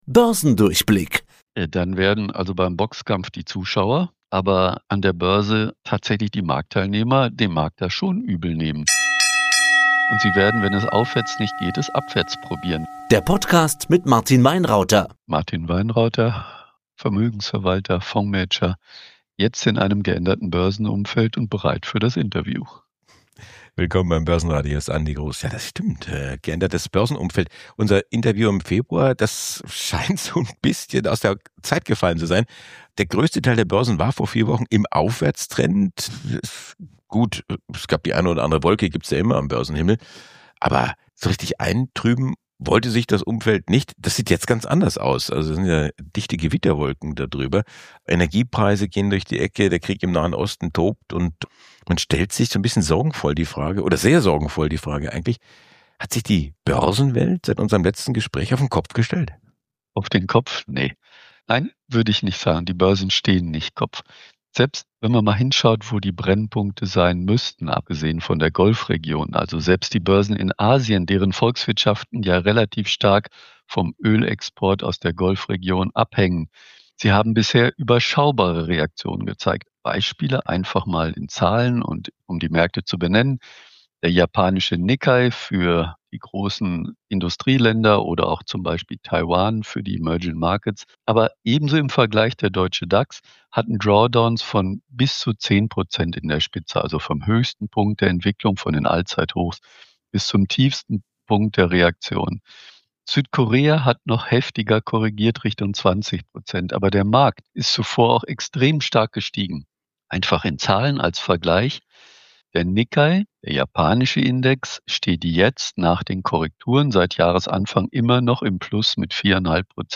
Ein Gespräch über Strategie statt Panik.